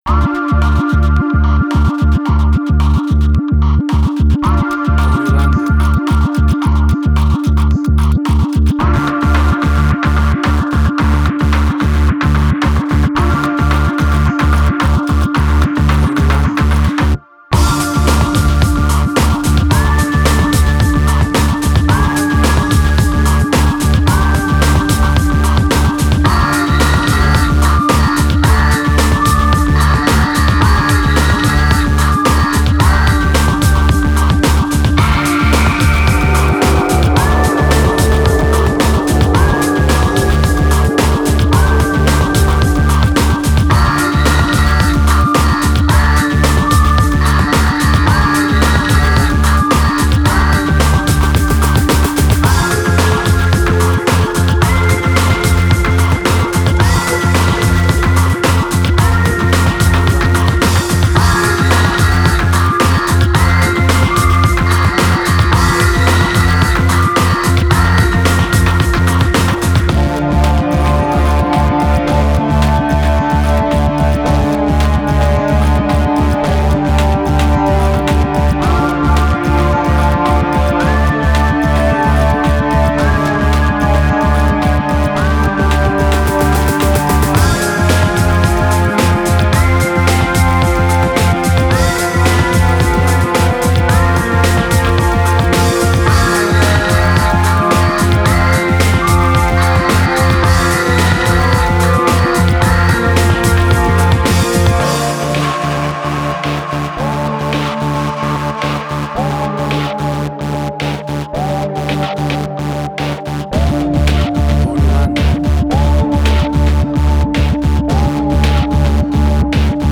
Post-Electronic.
Tempo (BPM): 110